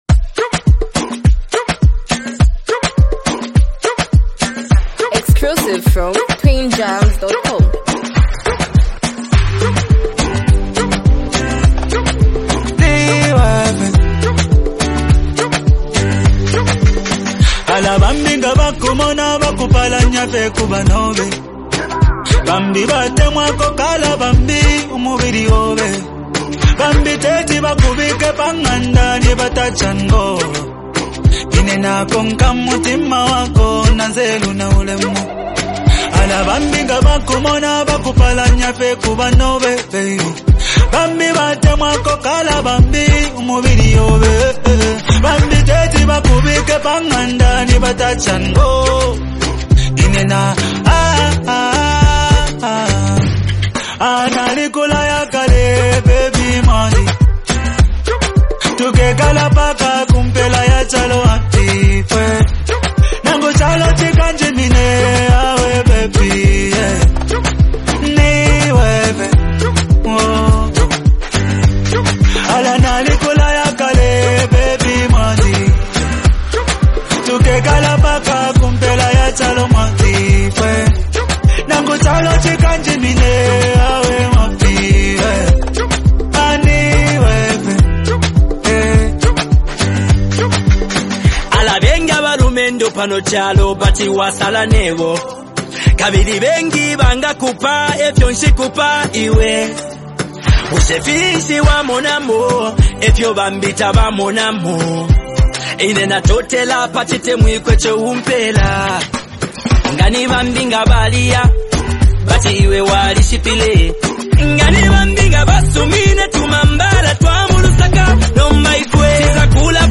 smooth, emotionally charged song